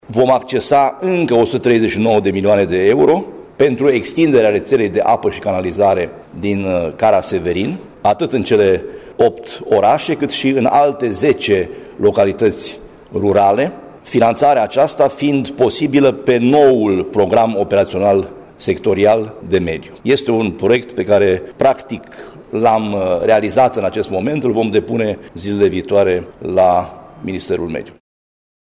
Proiectul este realizat şi va fi depus zilele viitoare la Ministreul Mediului, a afirmat preşedintele Consiliului Judeţean Caraş-Severin, Sorin Frunzăverde: